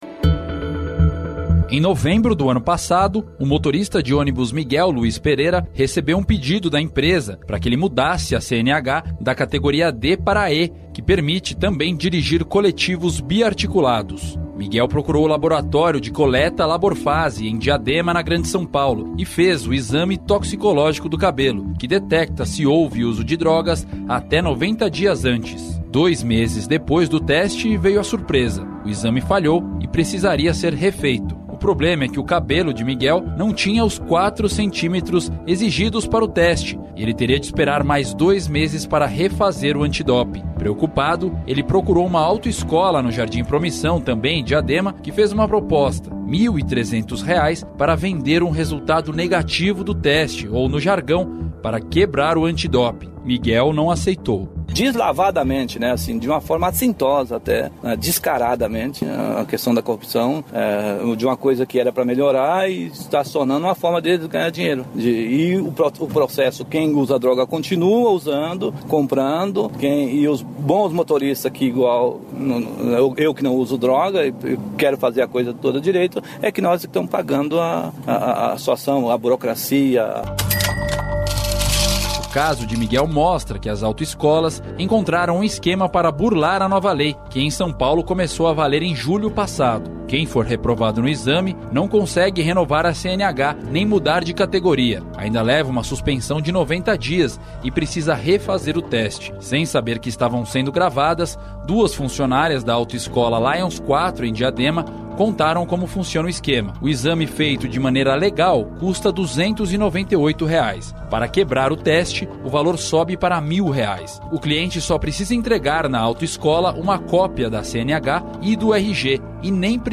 Reportagem 2: